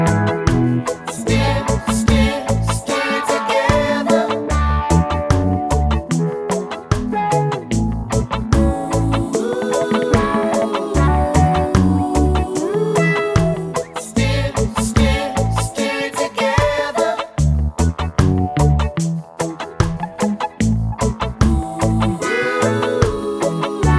no Backing Vocals Reggae 3:56 Buy £1.50